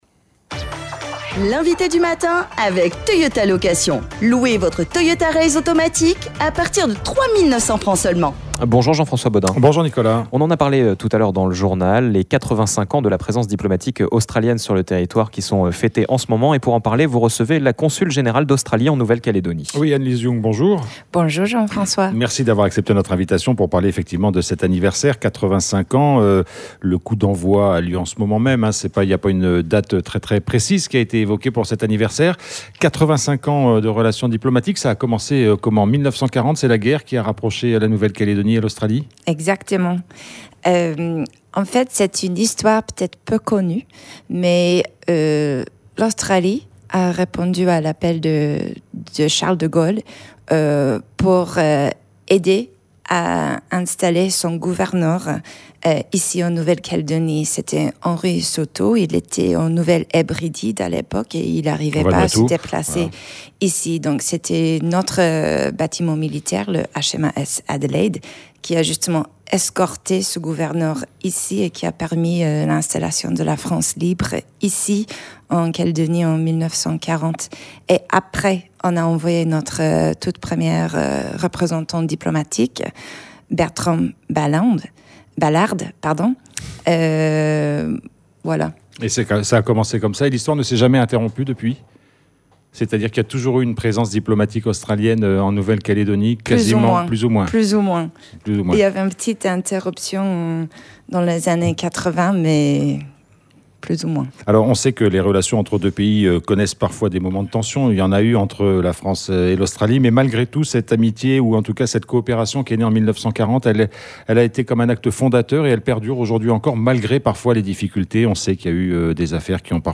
Il est question des 85 ans de la présence diplomatique australienne sur le Territoire. Comment la relation Australie / Nouvelle-Calédonie a-t-elle évoluée ? Quels sont les axes forts de coopérations ? Nous en parlons avec la consule générale d’Australie en Nouvelle-Calédonie Annelise Young.